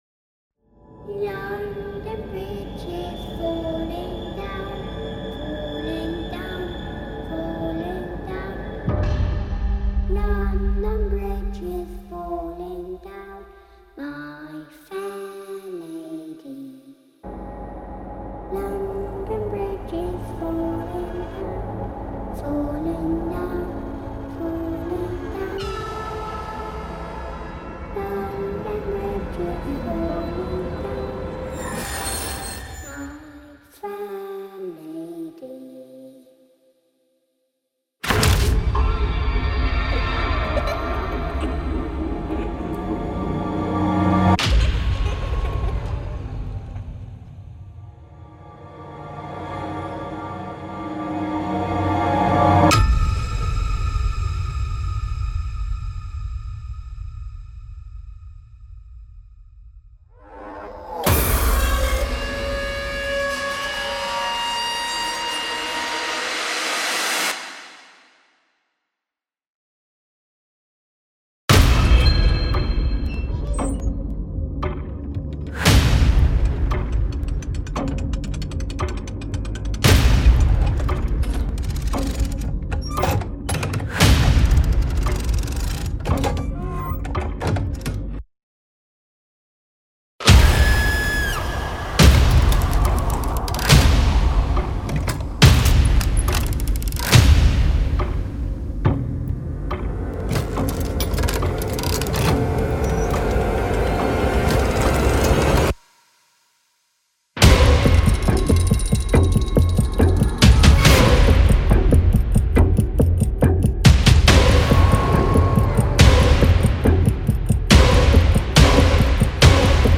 Creepy Nursery Rhymes